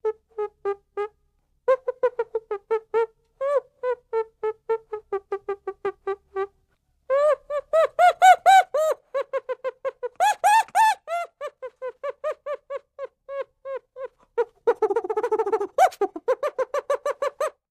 Animals-Monkeys|Chimpanzees | Sneak On The Lot
Chimpanzee various vocalizations ( fake )